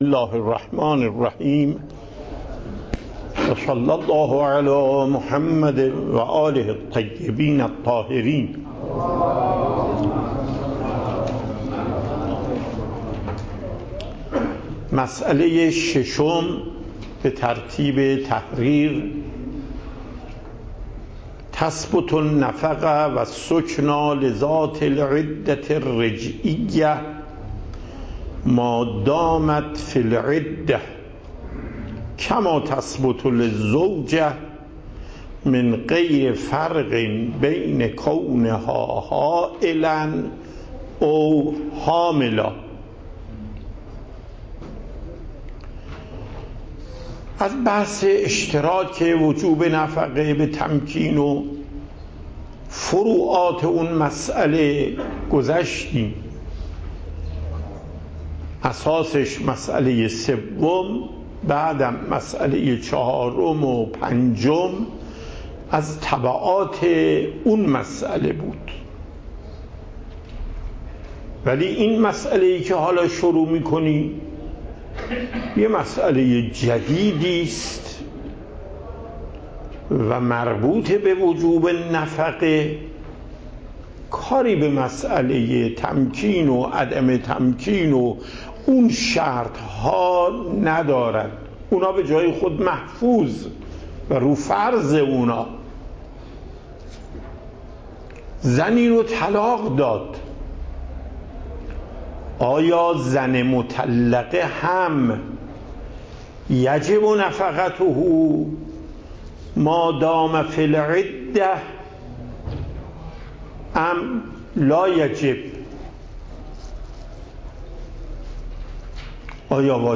صوت و تقریر درس پخش صوت درس: متن تقریر درس: ↓↓↓ تقریری ثبت نشده است.
درس فقه آیت الله محقق داماد